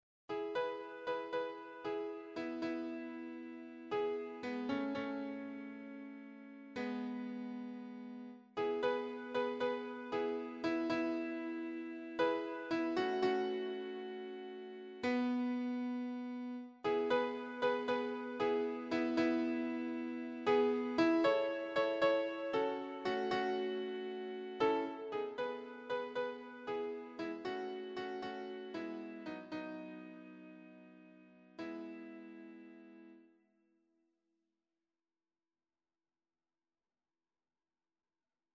choir SAA
Electronically Generated